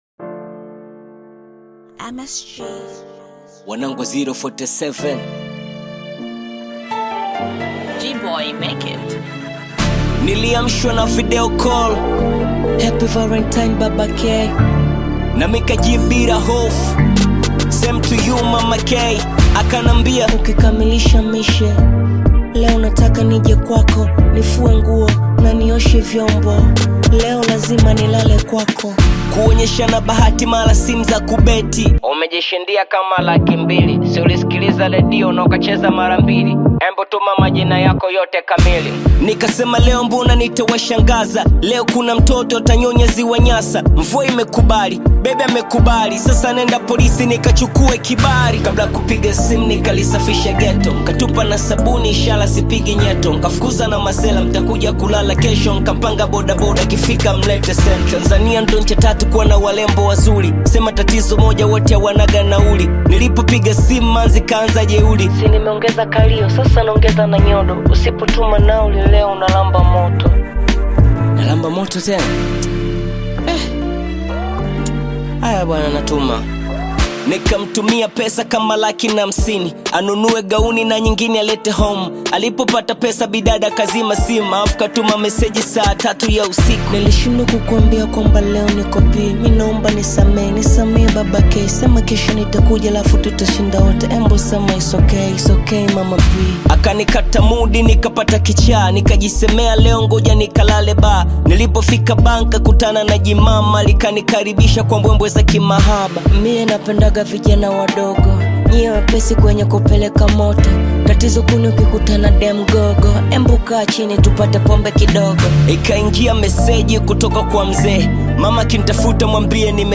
Tanzanian bongo flava artist, rapper, singer and songwriter